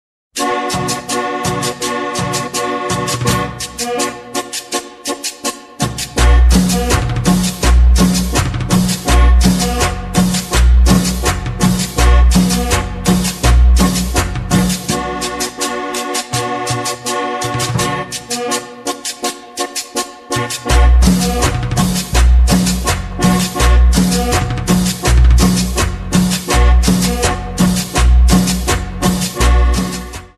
инструментал , латинские